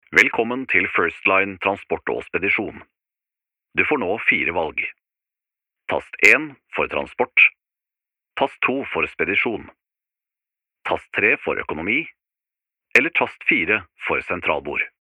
Comercial, Profundo, Natural, Seguro, Amable
E-learning